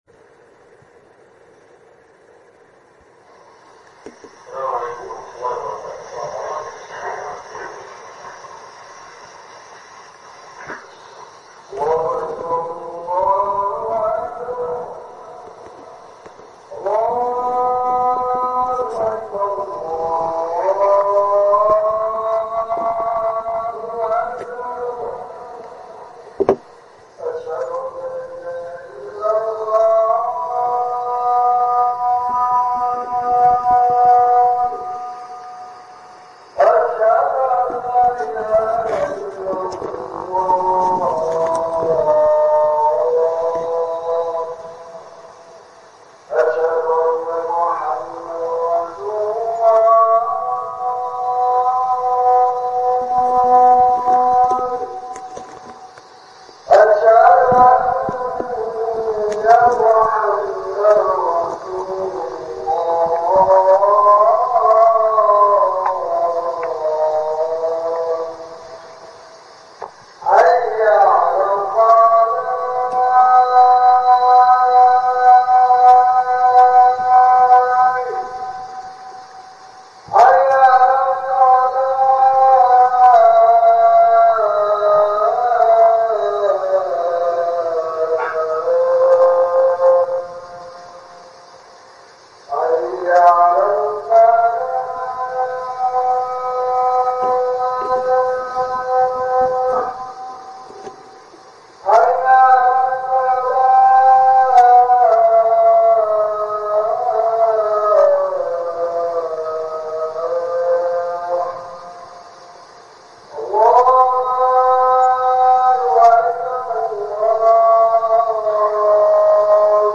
Day 7 – JUMAH – First Friday of Ramadan 2025 – Masjid Aurora – Al-Abrar Muslim Association – 33 Wellington Street East – Aurora – York Region, Ontario « 30 Masjids 🟩